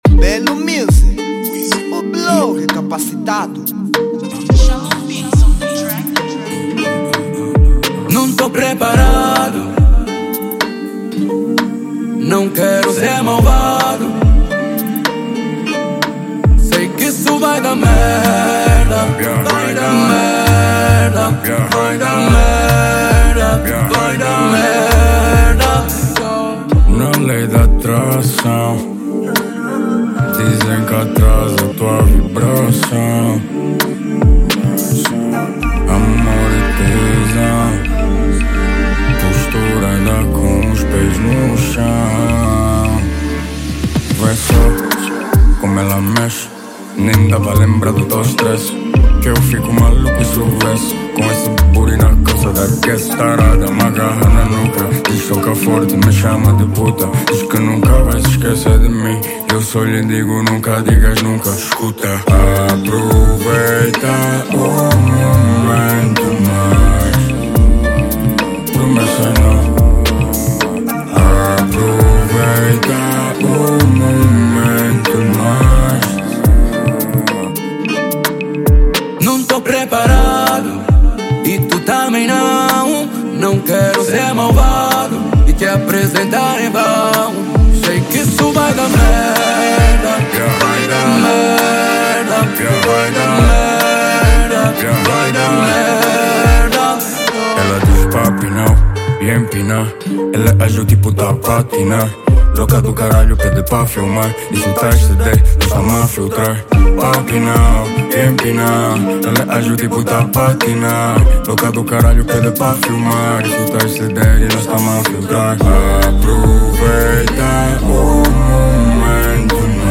Género : Afro Beats